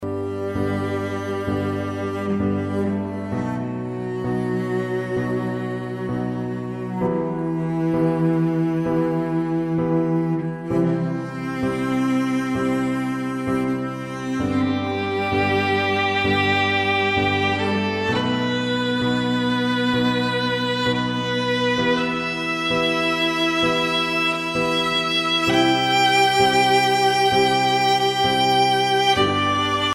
Relaxing Violin and Heavenly Cello Music